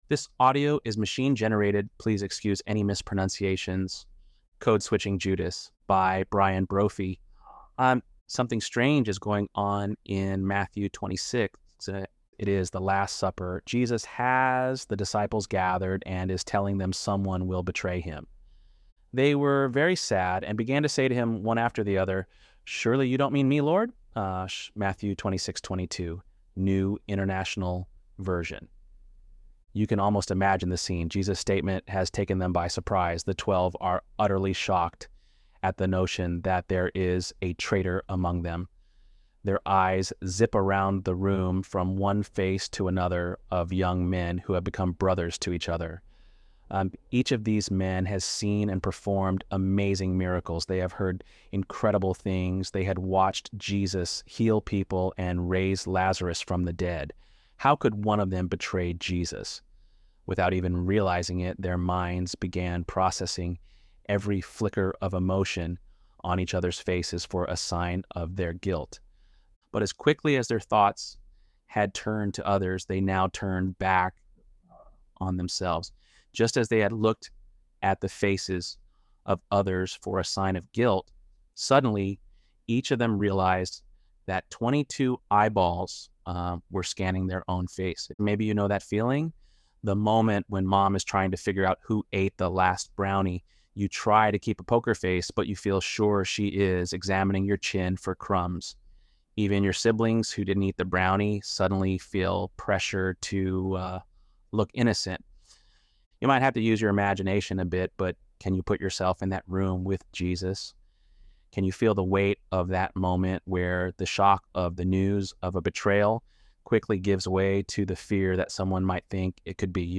ElevenLabs_5.28_Judas.mp3